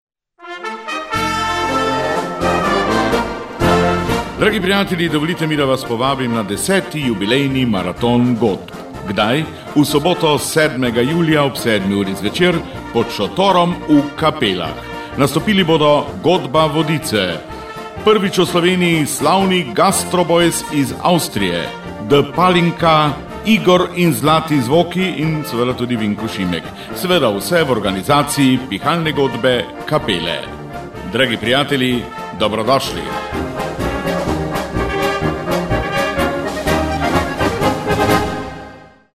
7. julij 2012... vabilo v glasbeni obliki...